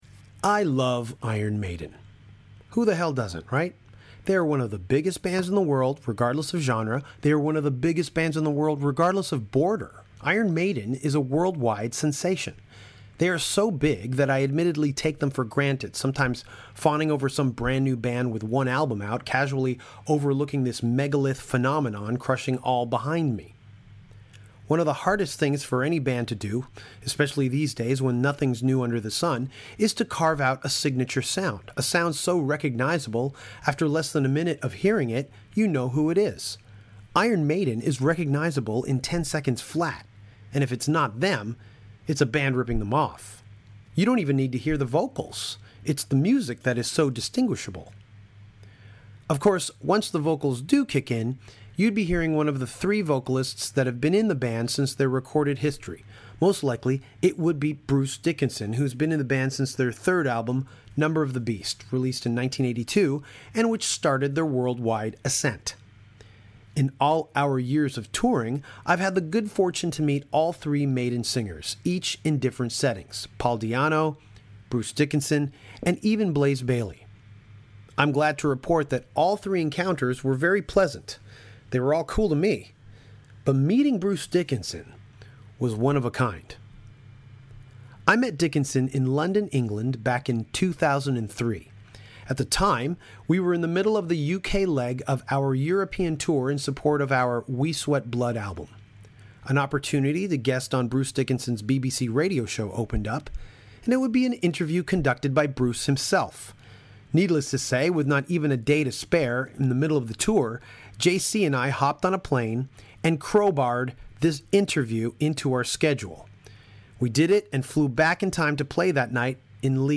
Bruce Dickinson of Iron Maiden sat down with Danko back in 2003 to talk about singing techniques. This discussion originally aired back when Danko was conducting his “Singer’s Clinic” series.